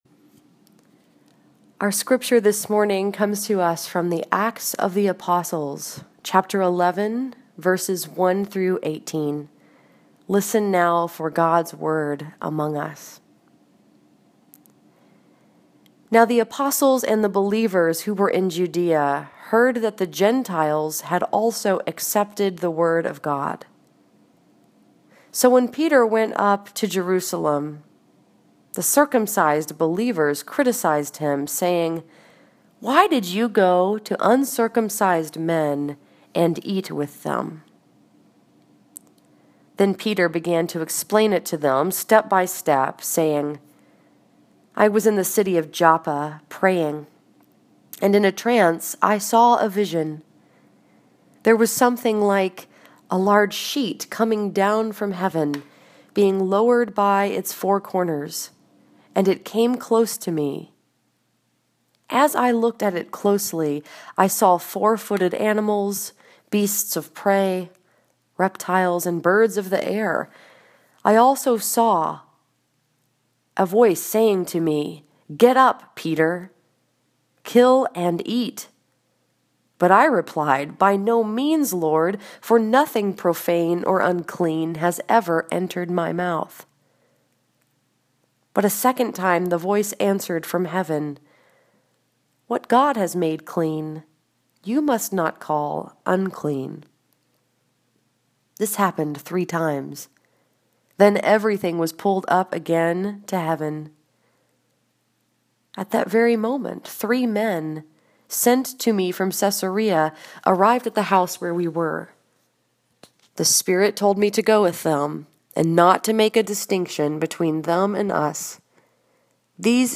[1] This sermon was preached at St. Andrew’s Presbyterian Church in Dearborn Heights, Michigan and was focused upon the story that is told in Acts 11:1-18.